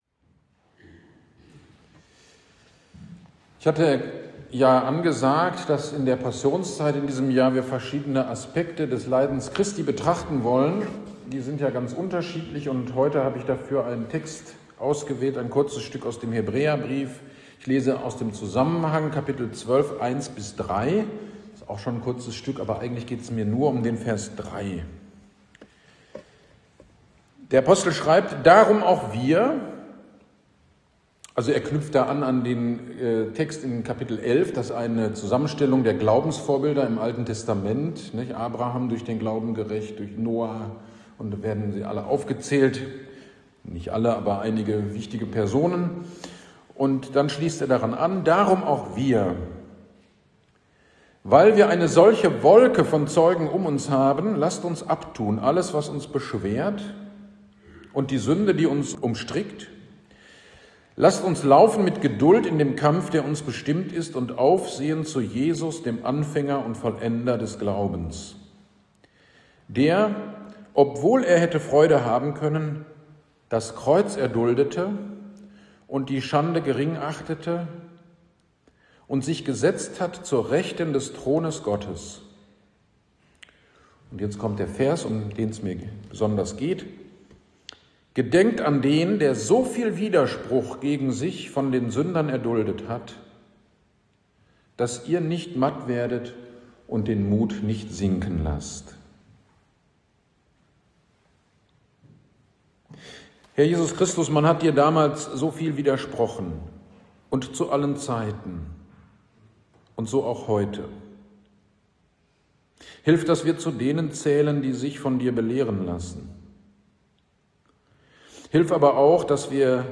GD am 29.03.26 Predigt zu Hebräer 12, 3